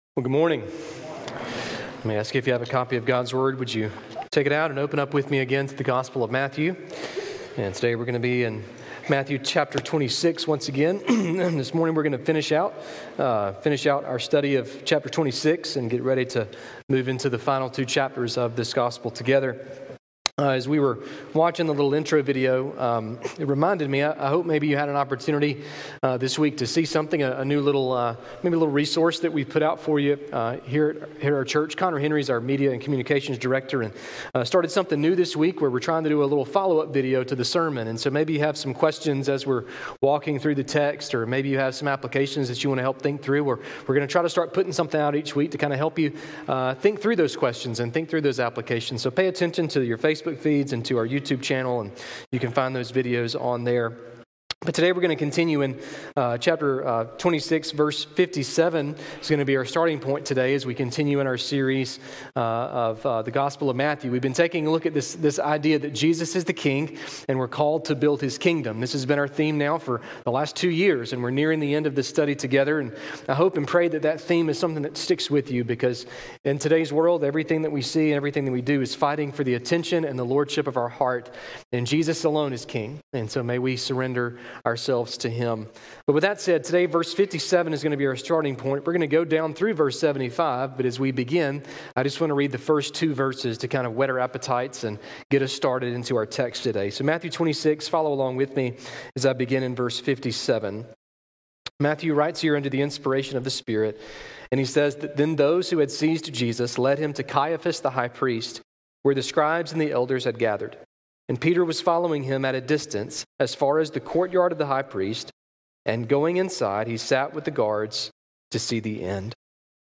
A message from the series "Your Kingdom Come."